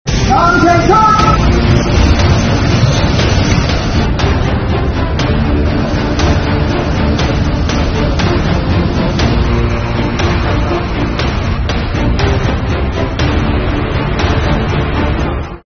重庆消防指战员火速集结